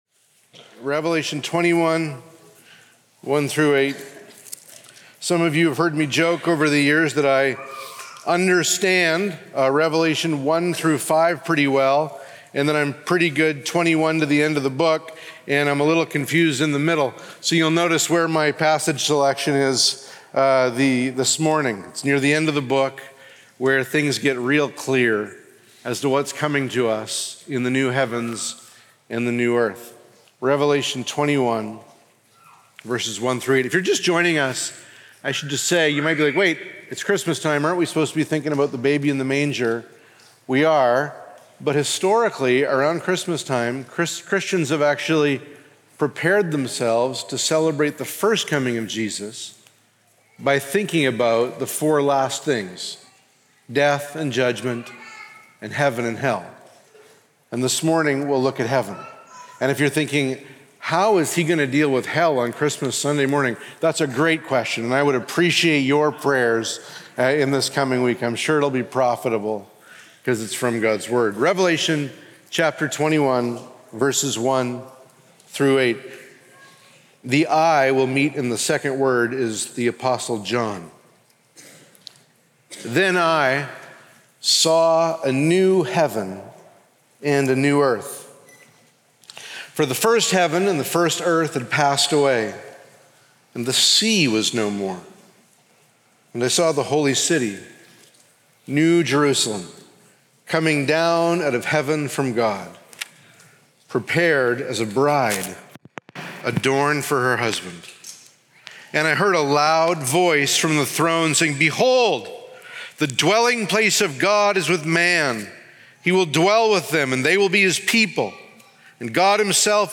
Sermons | Immanuel Baptist Church